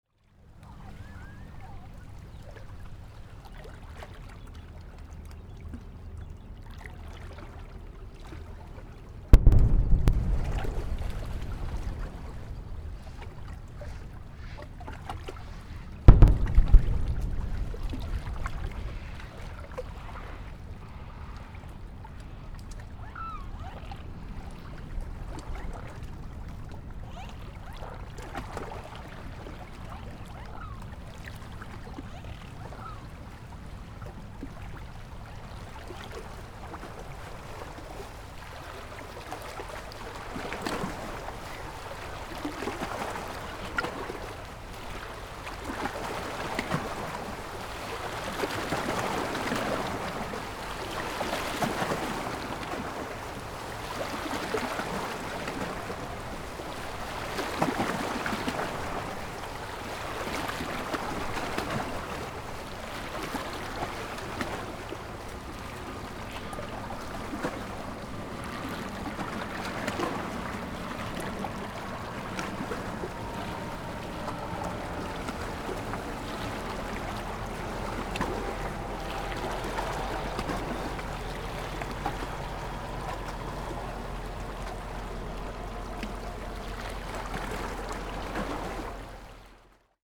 Am Ufer von Horn. Und hartnäckiger Widerstand im Aushub:
sprengung-am-bodensee.mp3